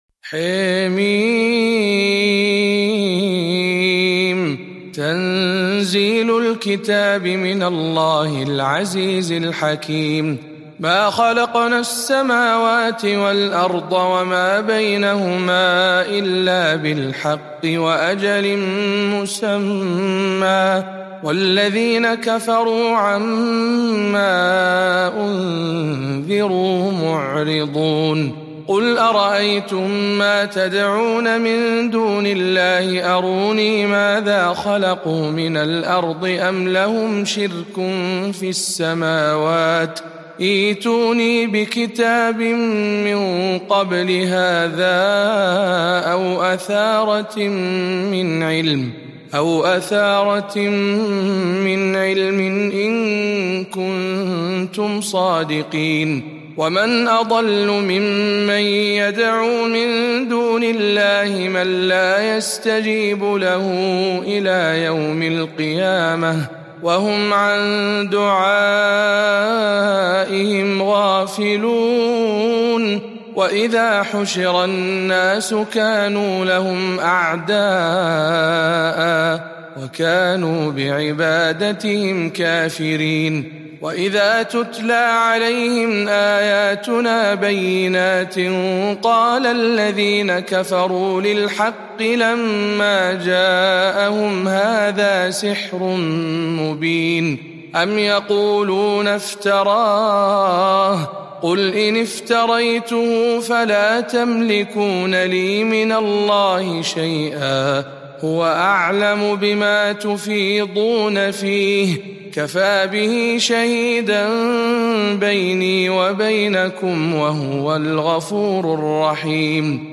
045. سورة الأحقاف برواية شعبة عن عاصم